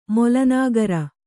♪ mola nāgara